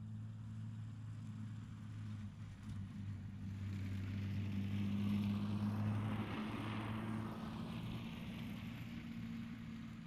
Zero Emission Snowmobile Description Form (PDF)
Zero Emission Subjective Noise Event Audio File (WAV)